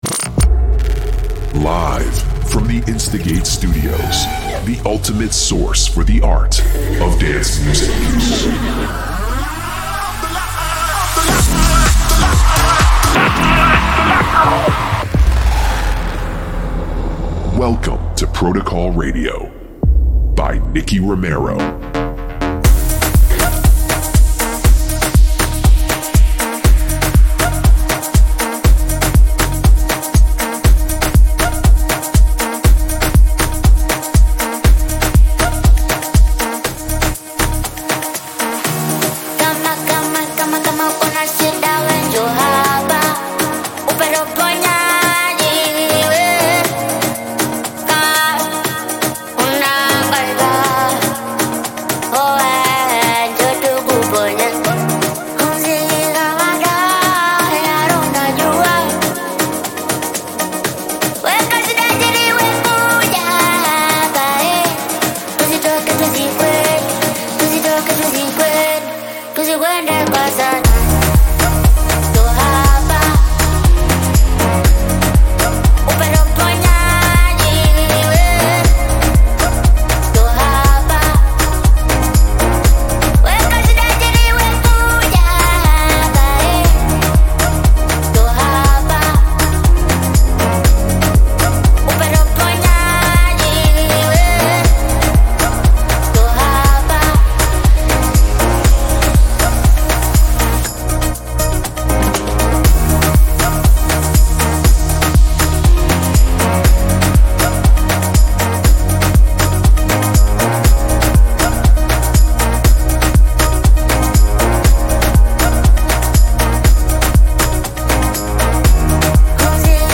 music DJ Mix in MP3 format
Genre: Electro House